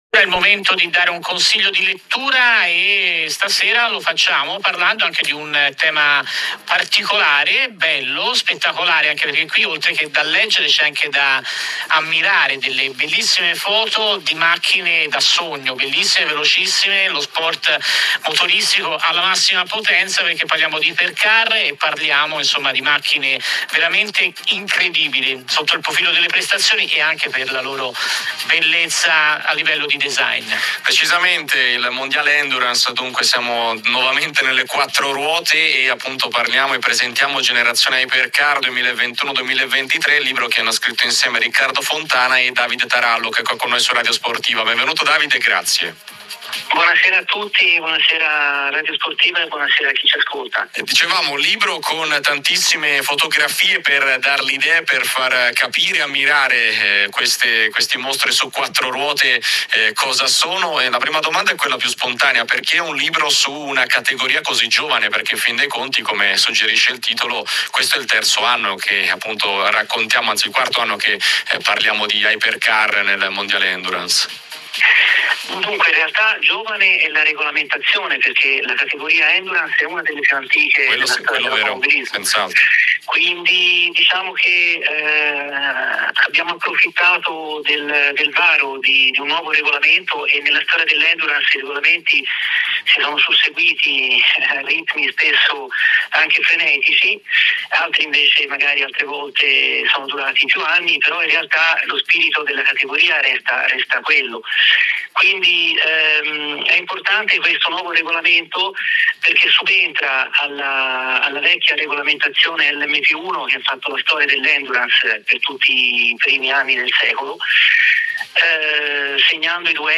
ha dedicato un servizio in diretta al libro Generazione Hypercar